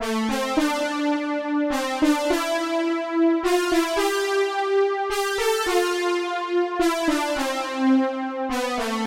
电影般的80年代合成器线1
描述：俗气的80年代动作电影音乐。